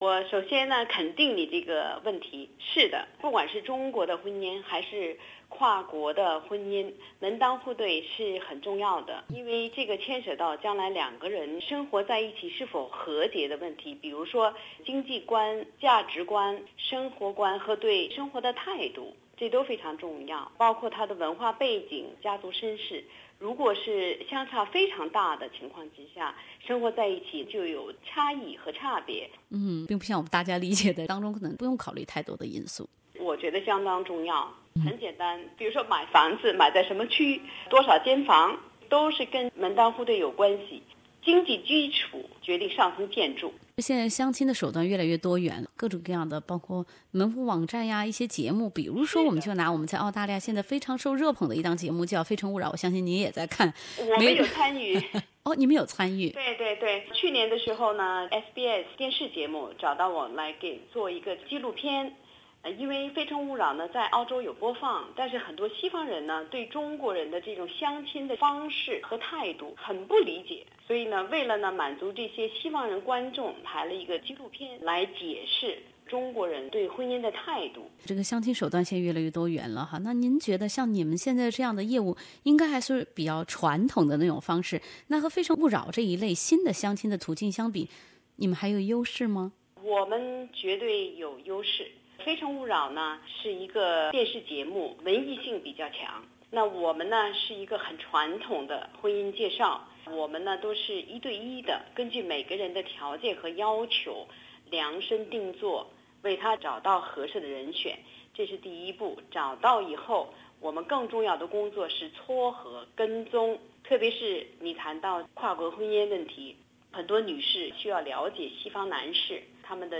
进行了采访